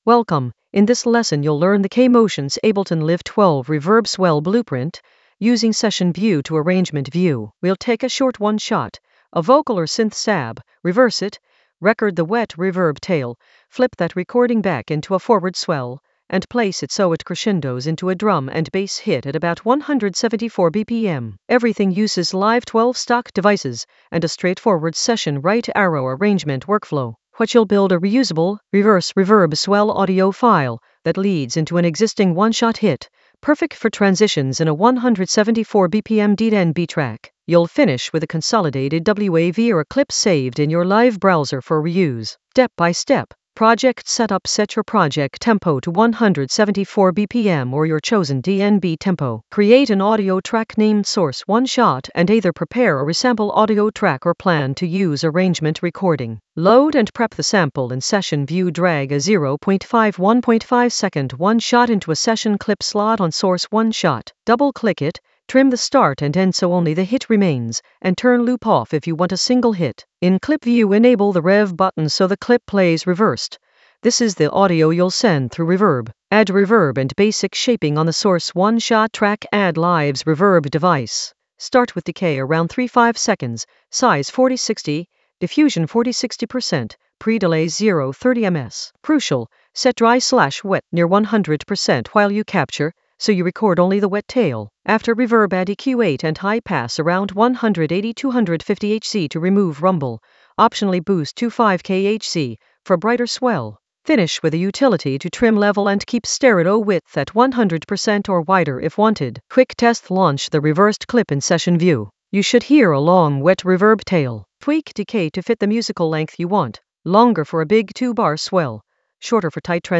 An AI-generated beginner Ableton lesson focused on K Motionz Ableton Live 12 reverb swell blueprint using Session View to Arrangement View in the Sampling area of drum and bass production.
Narrated lesson audio
The voice track includes the tutorial plus extra teacher commentary.